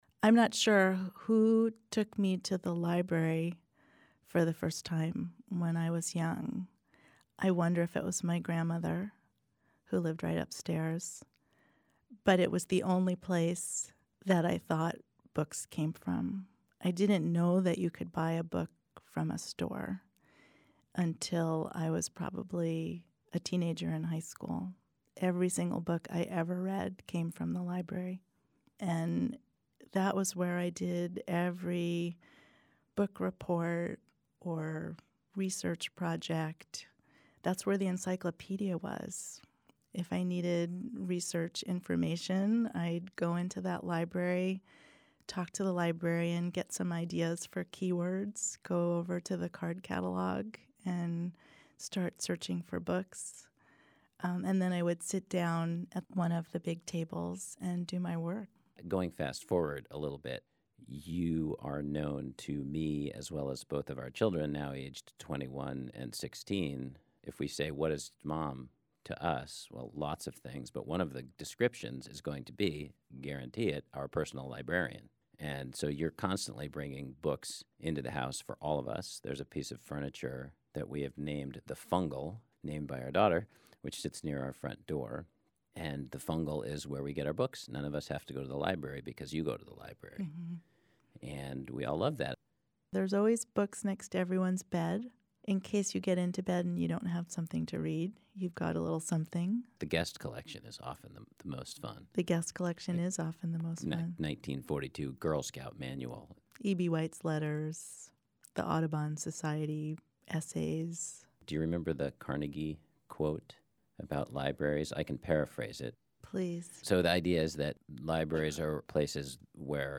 In 2014, we partnered with StoryCorps to ask Library patrons and staff, “What does The Seattle Public Library mean to you?” Listen to recordings of participants telling their personal Library stories.